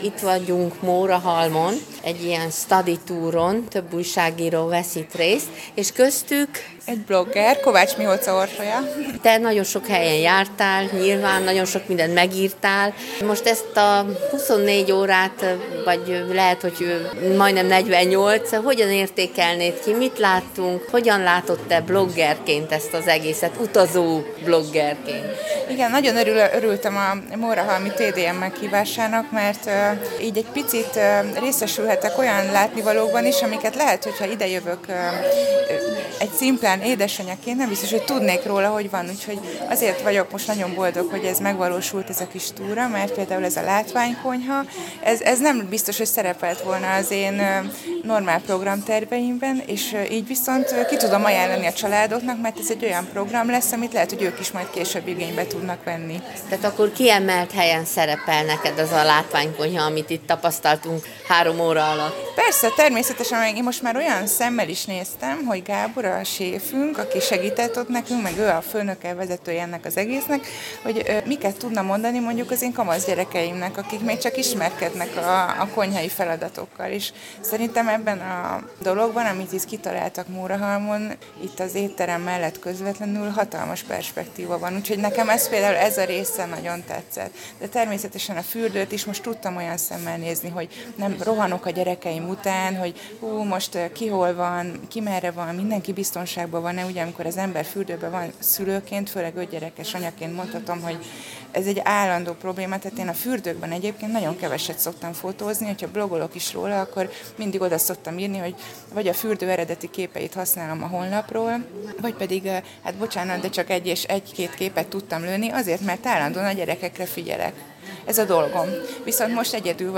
De hadd beszéljenek a vendéglátóink, a szakemberek!